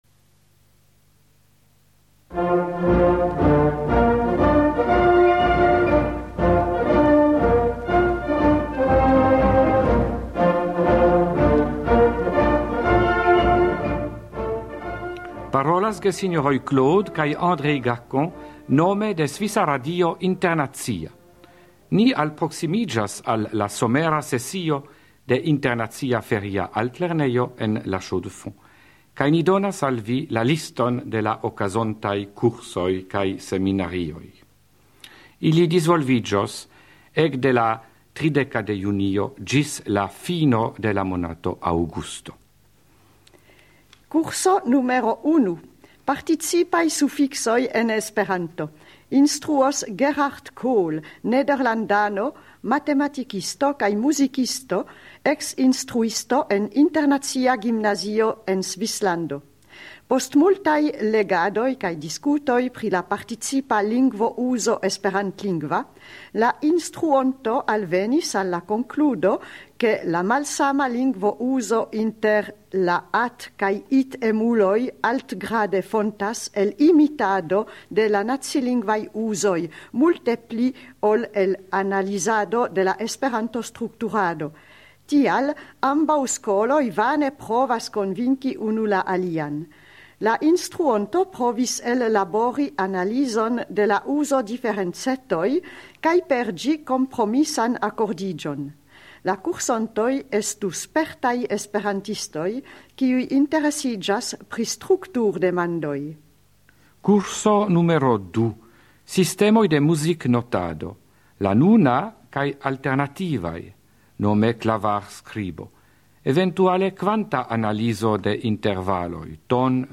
Radioprelegoj